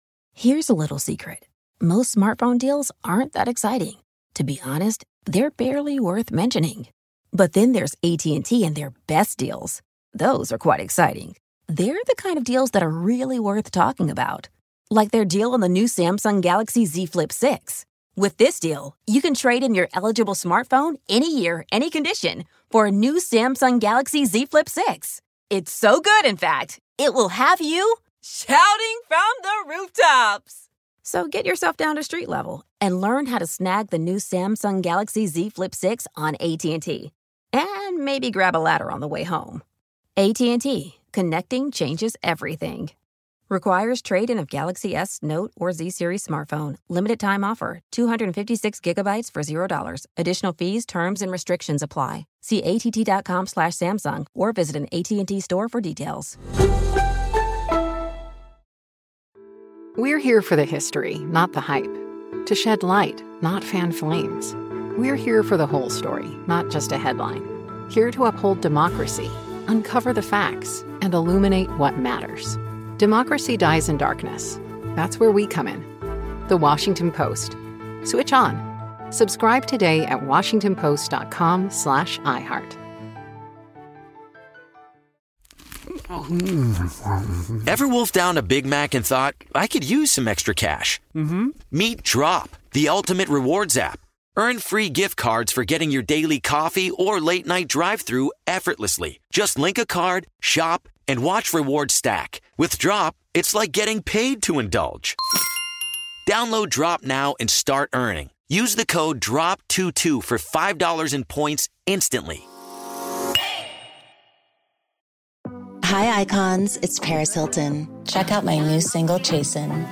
But it would take the world some time to believe that the age of flight had begun, with the first powered machine carrying a pilot. Here to tell the story from his bestselling biography, The Wright Brothers, is David McCullough.